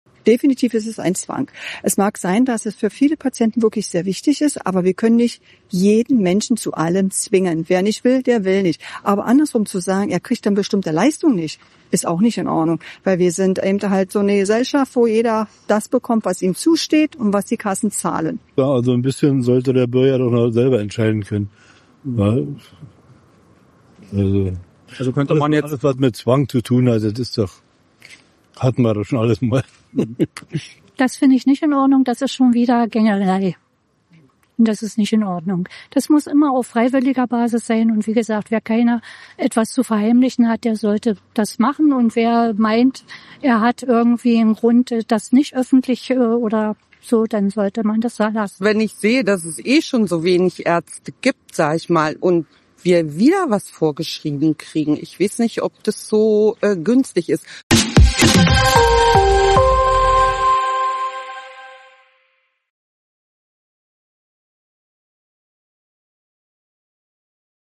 eigentlich die Bürger bei Berlin zu diesen Plänen?